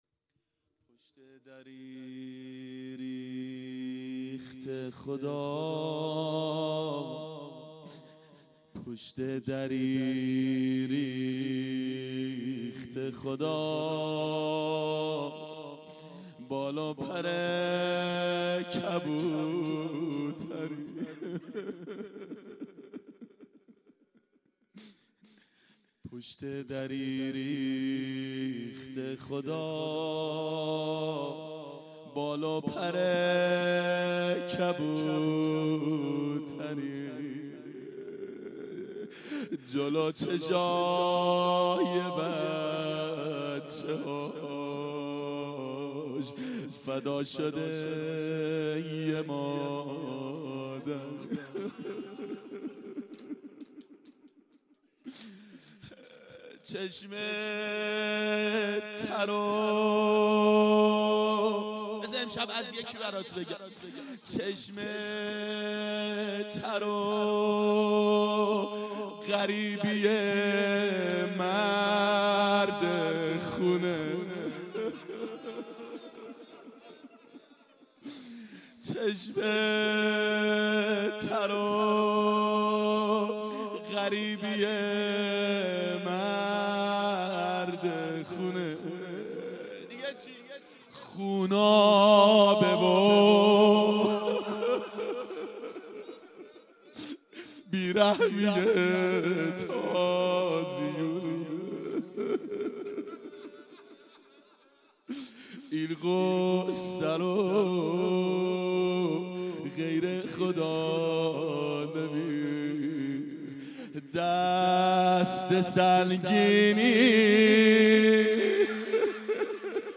روضه حضرت زهرا سلام الله علیها
دهه اول صفر سال 1392 هیئت شیفتگان حضرت رقیه سلام الله علیها
02-روضه-حضرت-زهرا.mp3